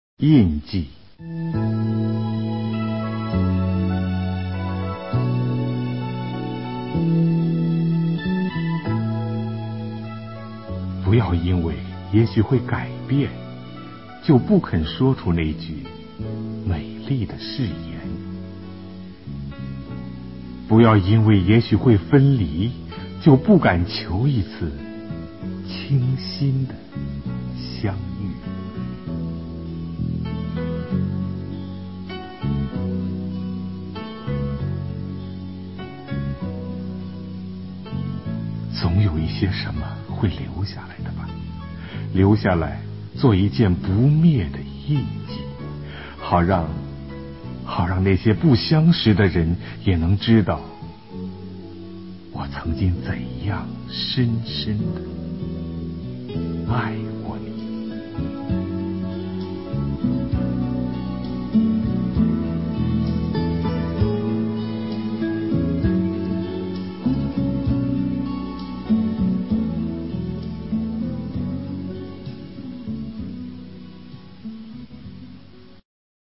首页 视听 经典朗诵欣赏 席慕容：委婉、含蓄、文雅